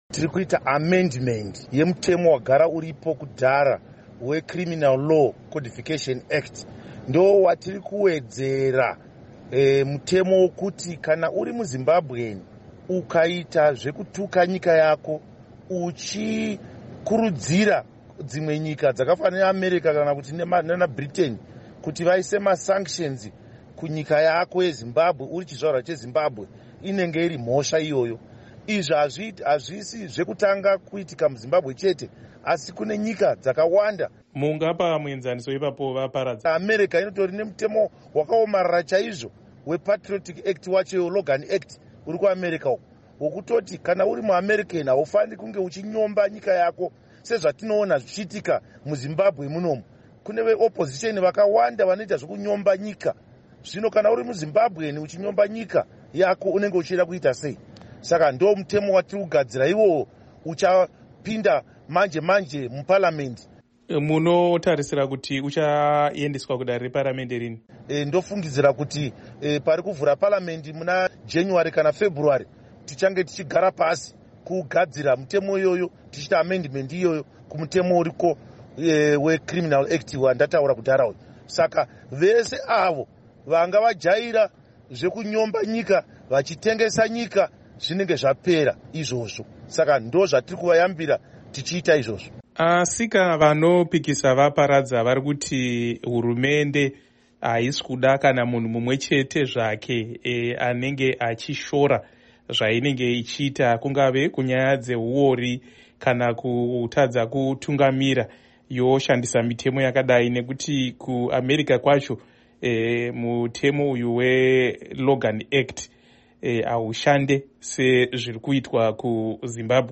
Asi vachitaura neStudio 7, mutevedzeri wegurukota rezvekuburitswa kwemashoko VaKindness Paradza vati hapana chakaipa pamutemo uyu sezvo.
Hurukuro naVaKindness Paradza